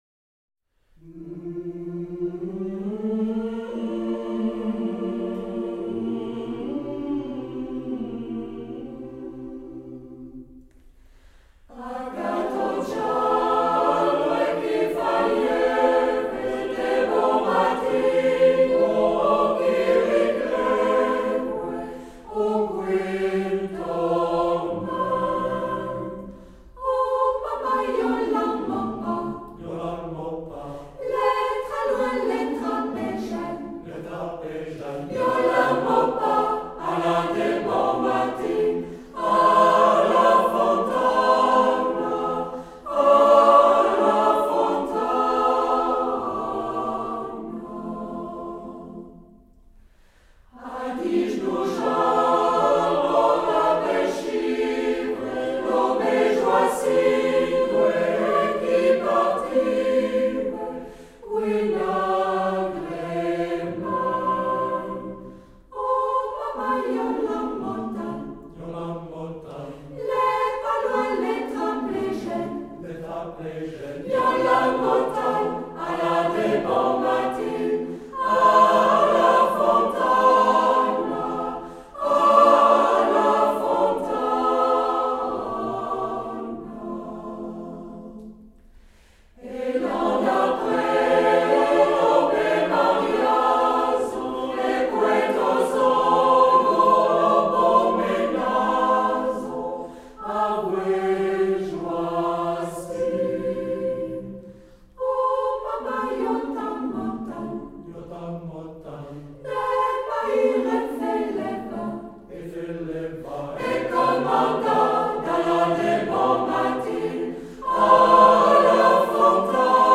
Western Switzerland. Trad.